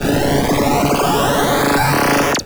Glitch FX 23.wav